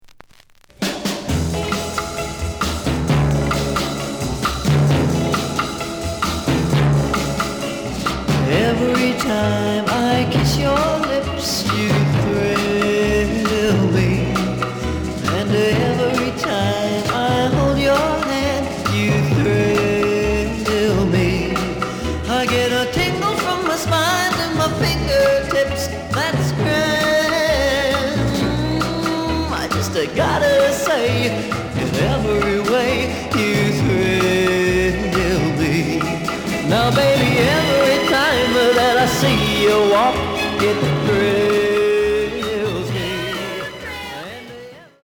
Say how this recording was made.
The listen sample is recorded from the actual item. B side plays good.)